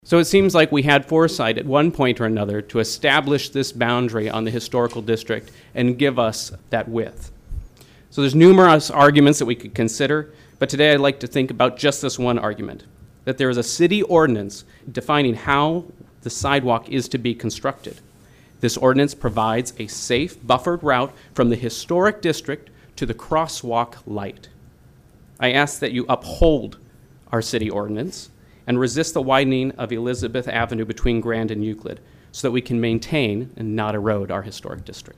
The comments came during the Pierre City Commission meeting’s public comment period.